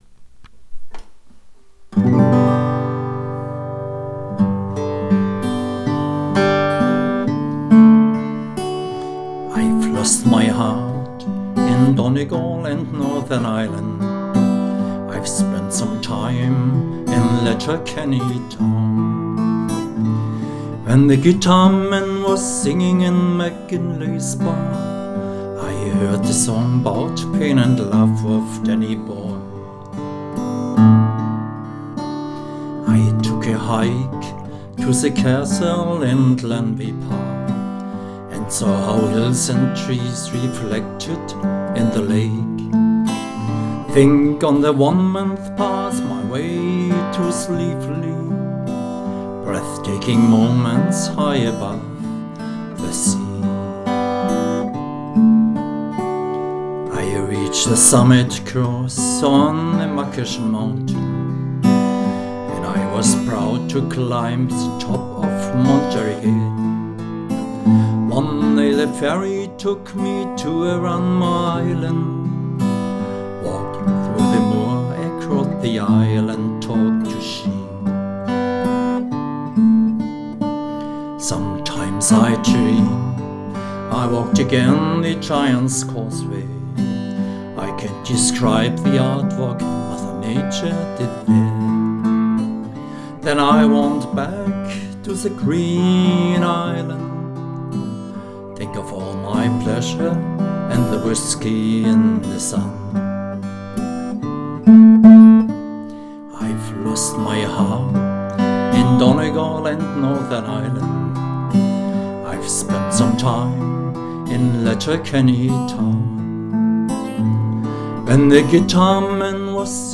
Auch sein Gitarrenspiel überzeugt durch unaufdringliche harmonische Musikalität!